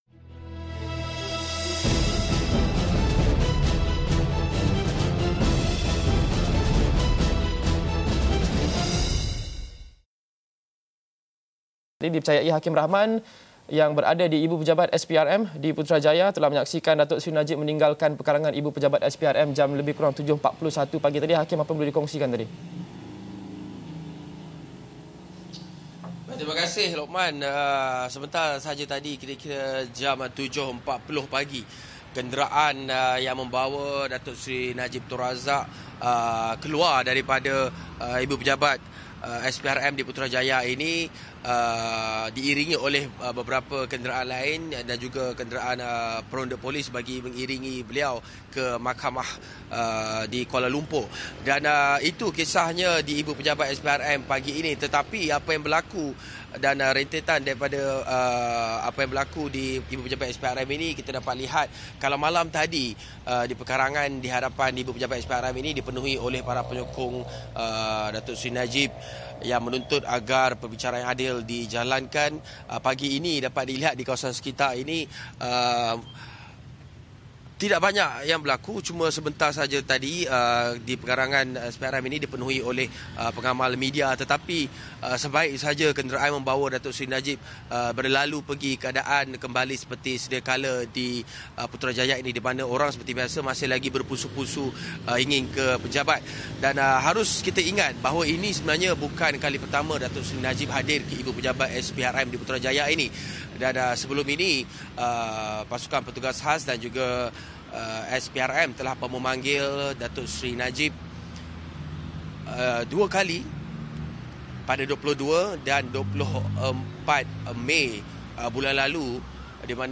yang berada di Bangunan SPRM, Putrajaya melaporkan perkembangan pendakwaan Datuk Seri Najib Razak.